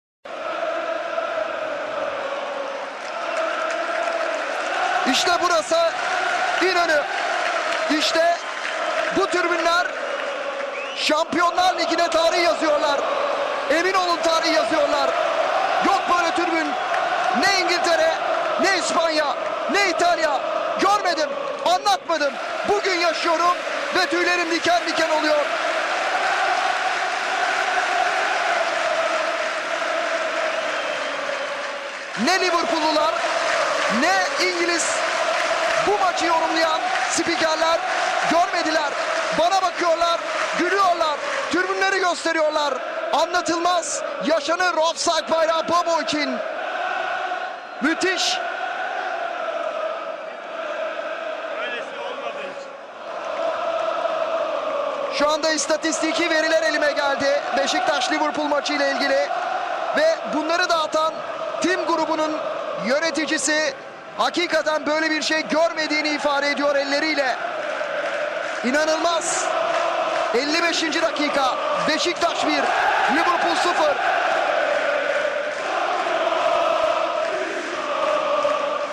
2007/08 Şampiyonlar Ligi A Grubu 3. maçında Beşiktaş ile Liverpool, İnönü'de mücadele verirken bu karşılaşmada UEFA tescilli 132 desibel ses rekoru kırılarak Dünya futbol tarihine geçti.